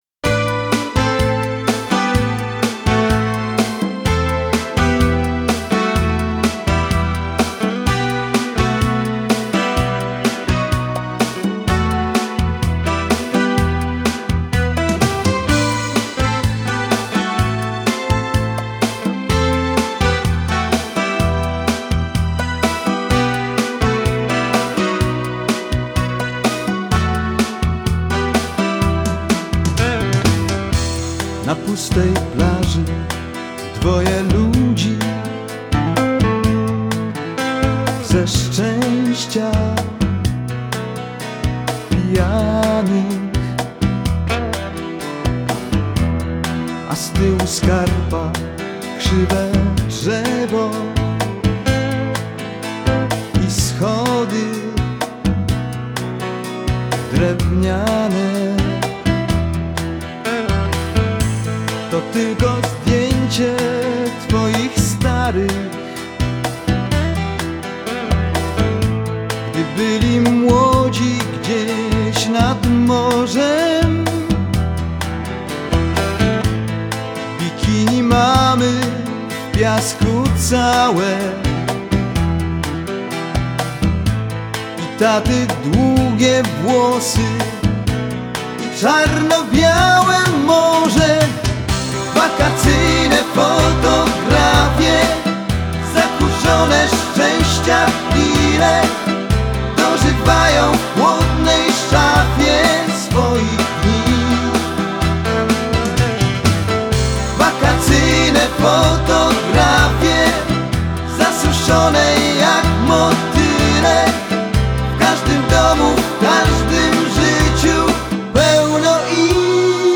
мелодичных композиций